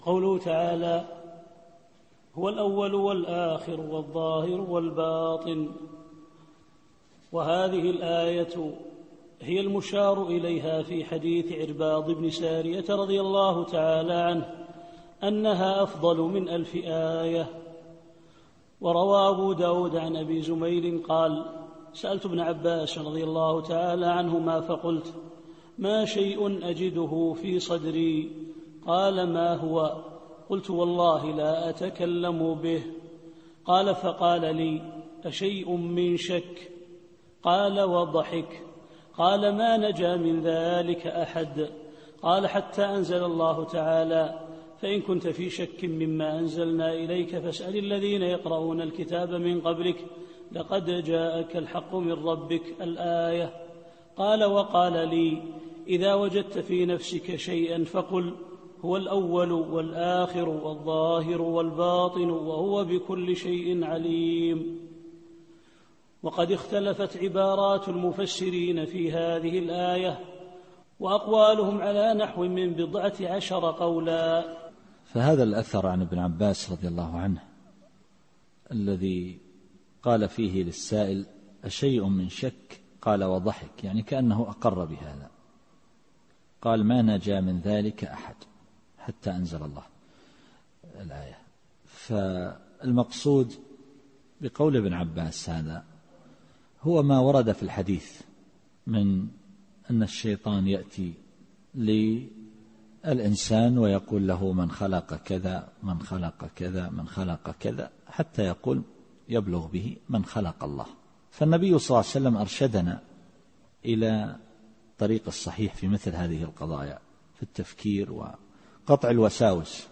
التفسير الصوتي [الحديد / 3]